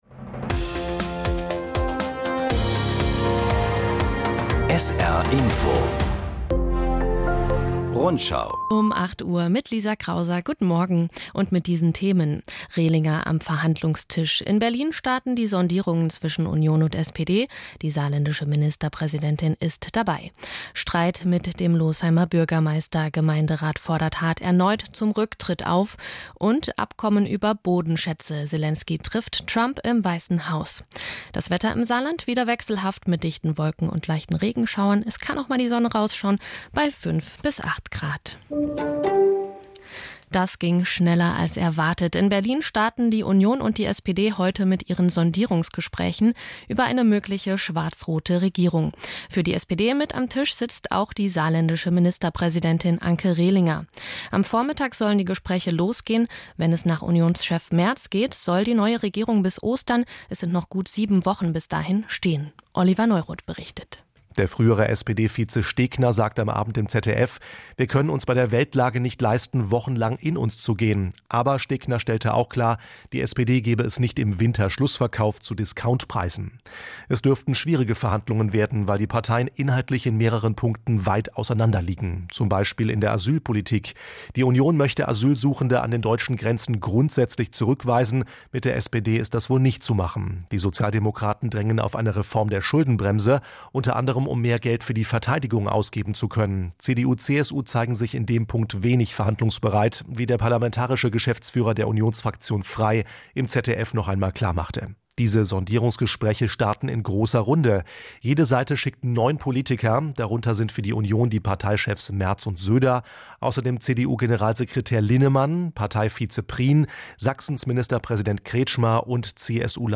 Zehn Minuten Information aus aller Welt und aus der Region … continue reading 5 episoade # Nachrichten